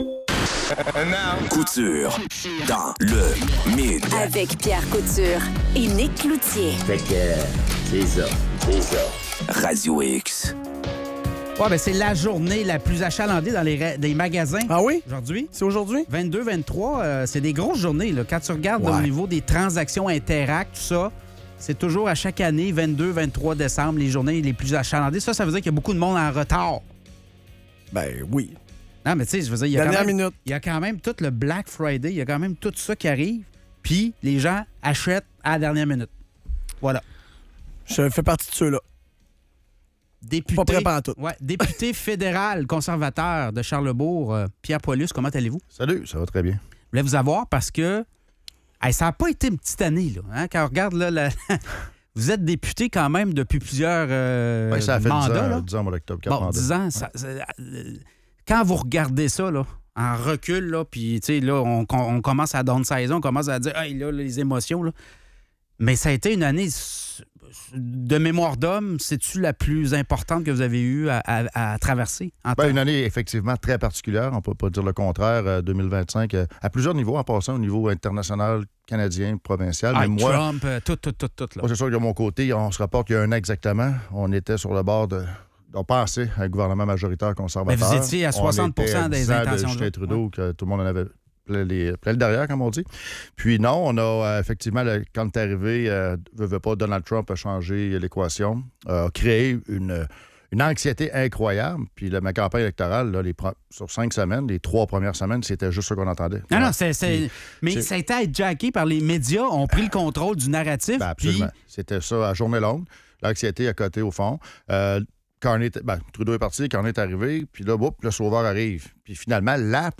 Entrevue avec PIERRE PAUL-HUS